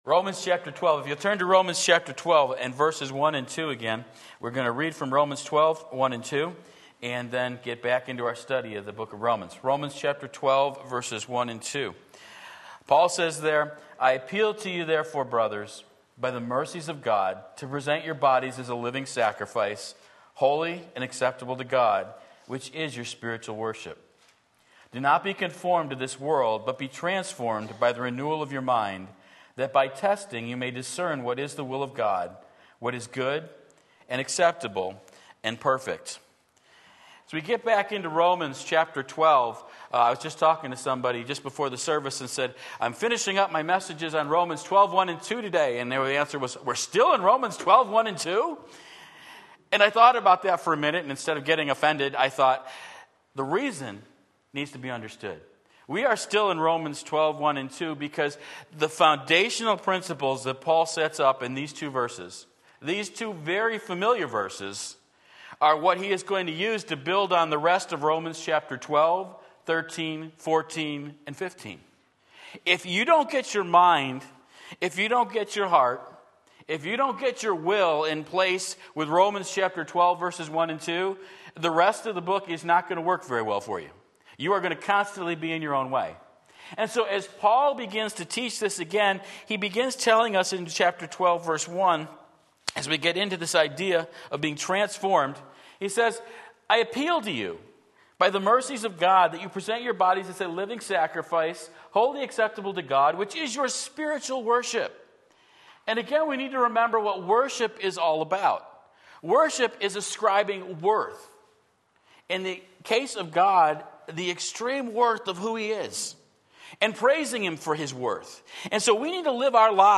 Sermon Link
Transformed to Discern God's Will Romans 12:1-2 Sunday Morning Service